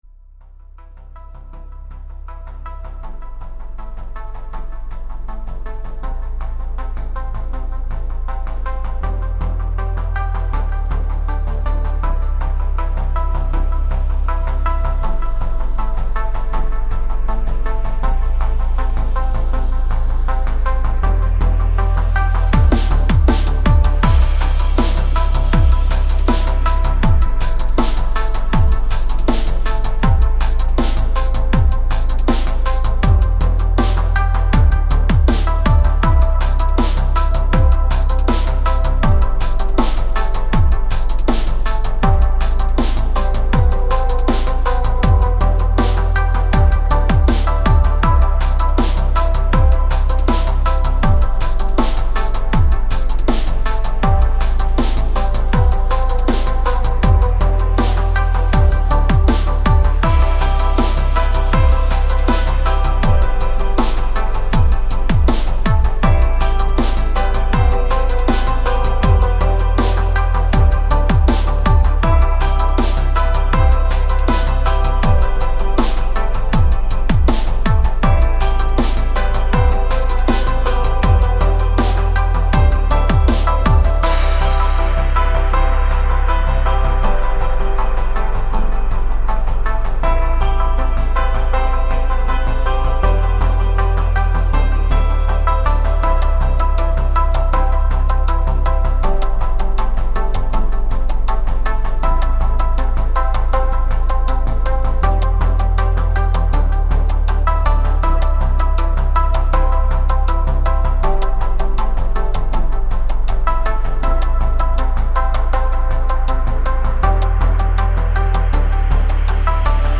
BPM-80 This was worked on over two days.
I like making these Chillstep songs
It's really calm and soothing :3
Very chill.